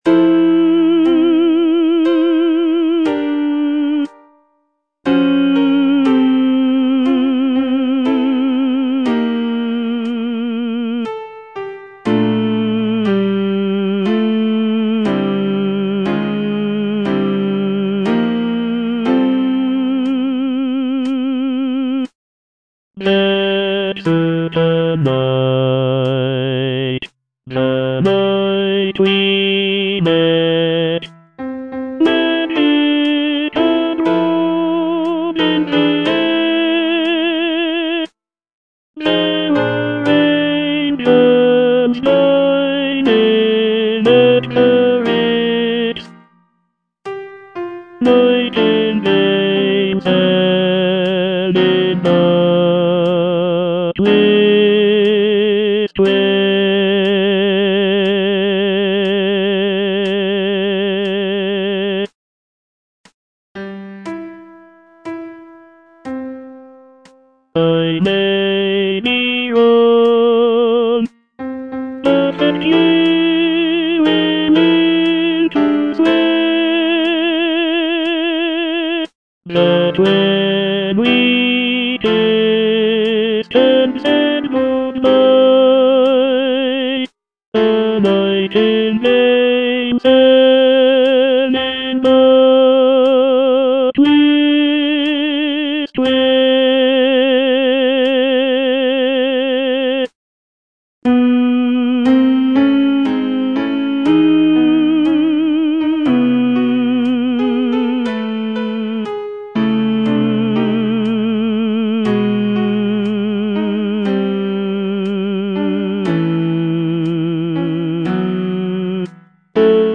Tenor (Voice with metronome)